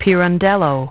Транскрипция и произношение слова "pirandello" в британском и американском вариантах.